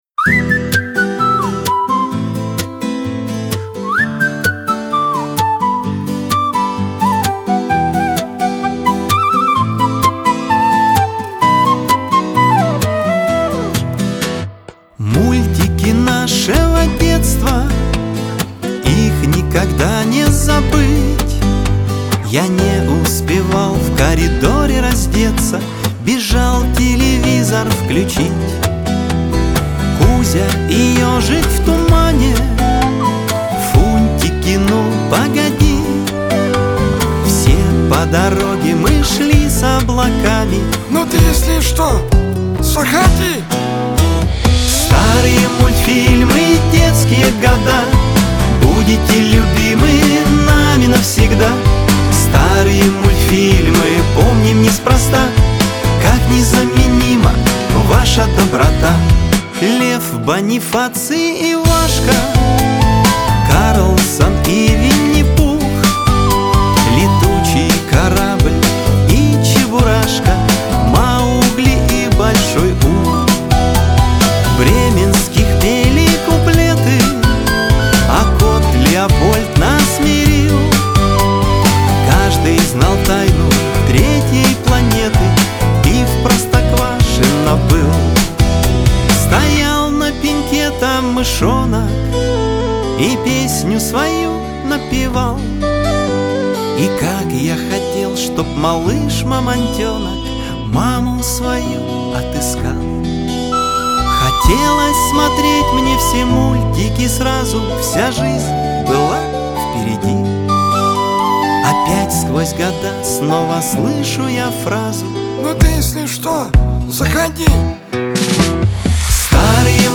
диско
эстрада
pop